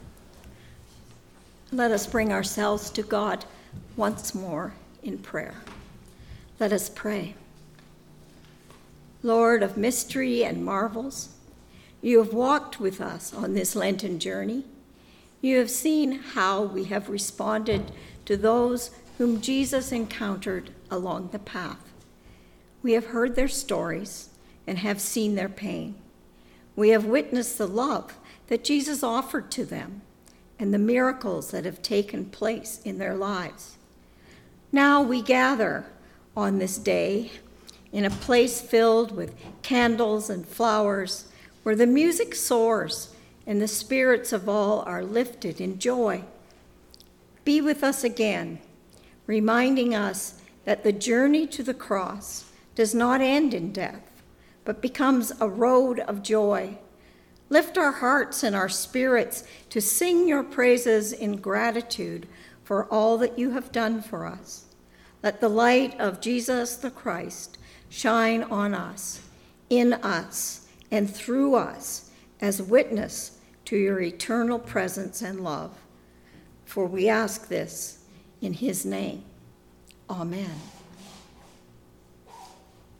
Prayers of the People & the Lord’s Prayer